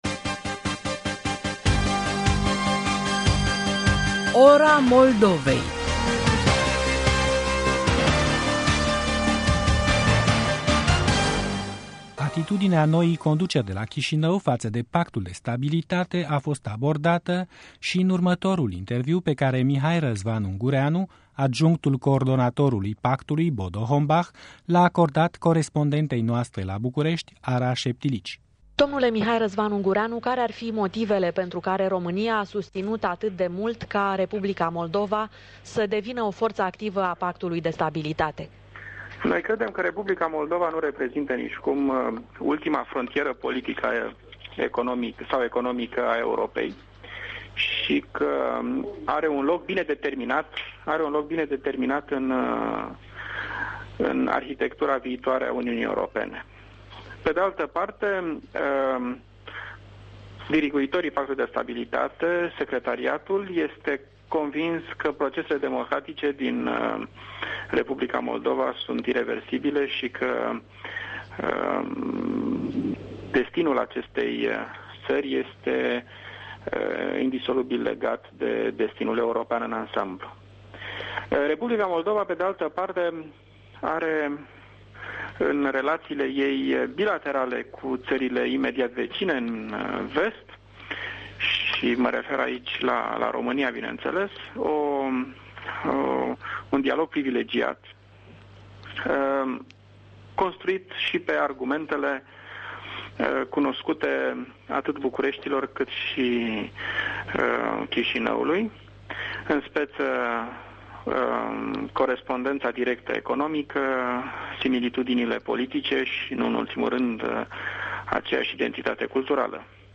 Un interviu cu diplomatul Mihai Răzvan Ungureanu, adjunct al coordonatorului Pactului de Stabilitate.
Un interviu pe tema beneficiilor Pactului de Stabilitate pentru R. Moldova